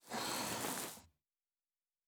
01_院长房间_扶正相框.wav